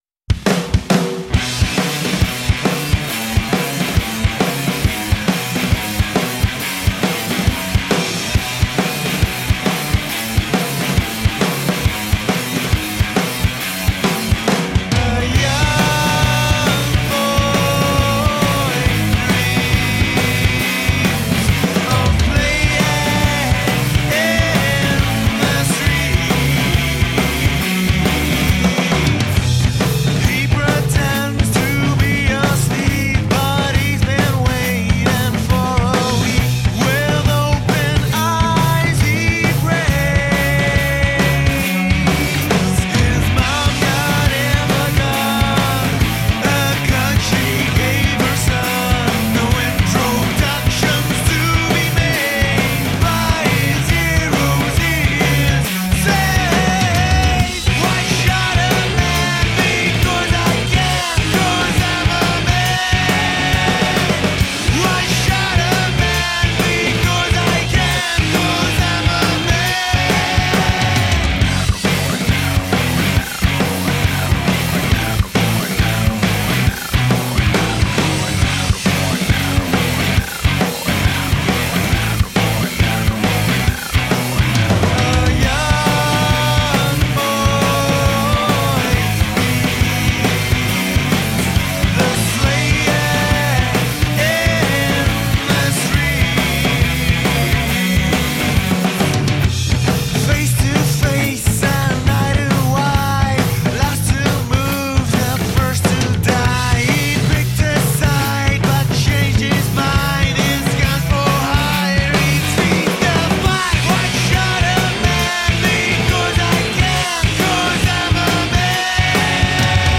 Cunning & ferocious rock music.
Tagged as: Hard Rock, Metal